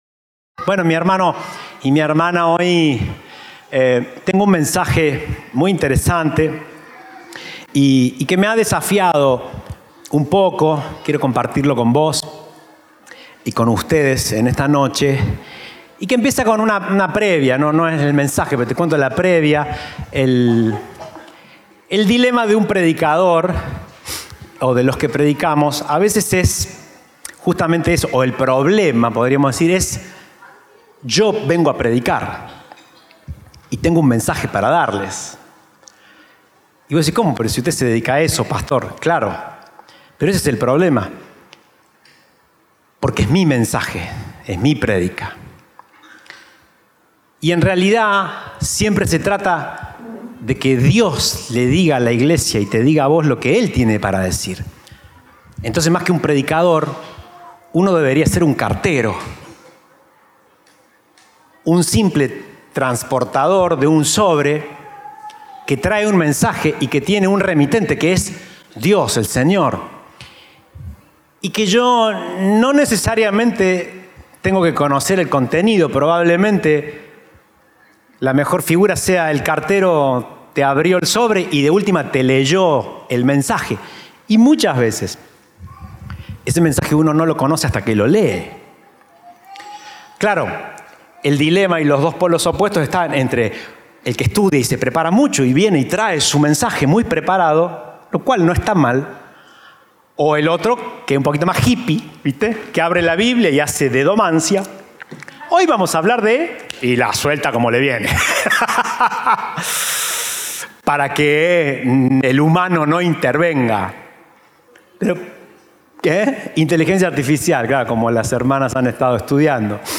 Compartimos el mensaje del Domingo 8 de Setiembre de 2024